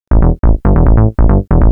Techno / Bass / SNTHBASS149_TEKNO_140_A_SC2.wav